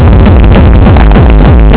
Le teknival c’est un grand rassemblement de musique électronique, dégustation de plats épicés, boissons enivrantes et plus si affinités. Cette musique envahi le corps à en faire perdre le rythme cardiaque, une danse individuelle à faire oublier les 40000 personnes autour.